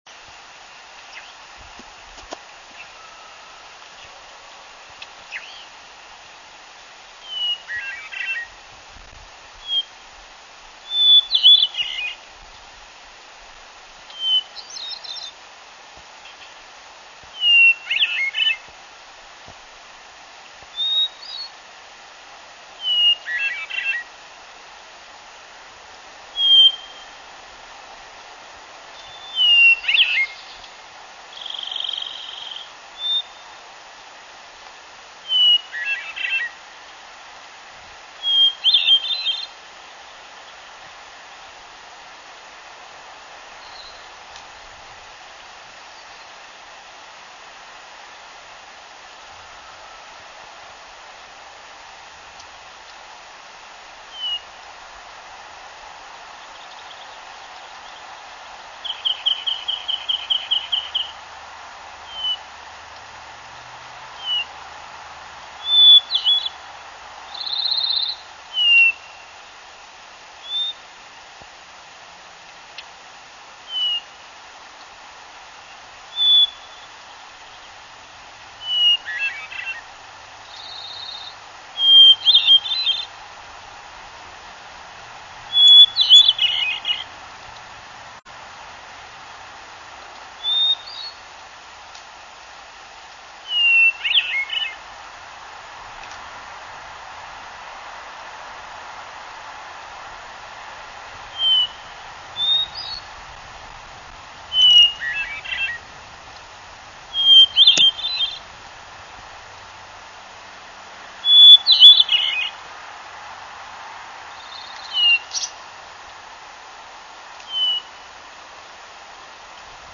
Pacific Hermit Thrush:  Yosemite National Park, Nunatak Nature Trail near Tioga Pass, elevation 9,700', 6/14/03, long recording (665kb) featuring a variety of songs and calls.  The Hermit Thrush begins with a single drawn out note like the Varied Thrush, followed by song sequences beginning at different points on the scale like the Wood Thrush executed in a spiraling manner like the Swainson's Thrush or Veery.
hermit_thrush_777.wav